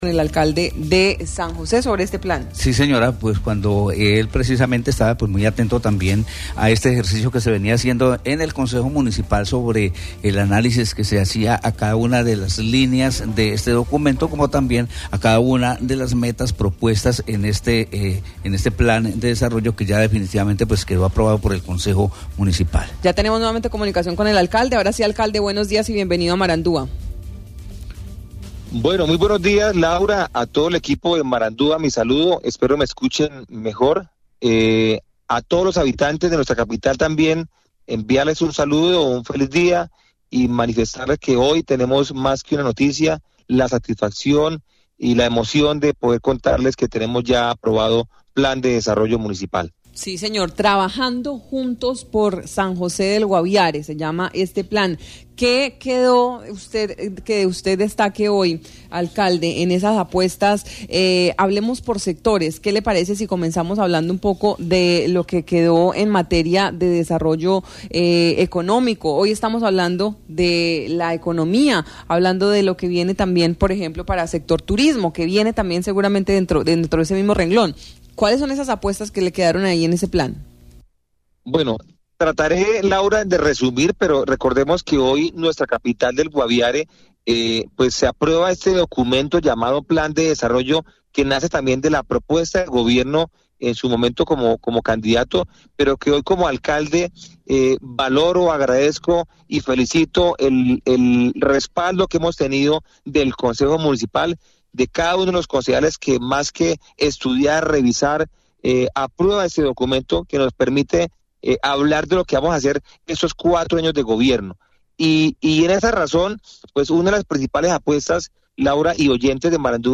El alcalde de San José del Guaviare, Willy Rodríguez, se refirió a la aprobación del Plan de Desarrollo municipal.